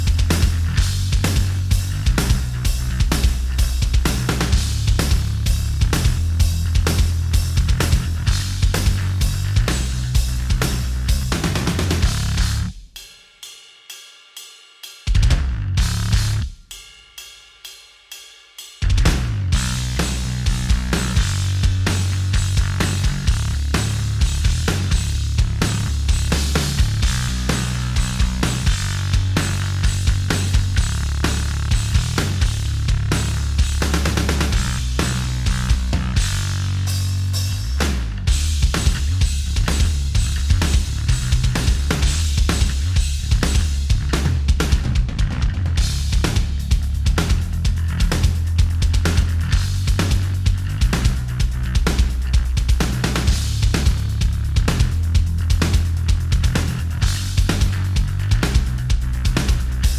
Minus Main Guitars For Guitarists 4:15 Buy £1.50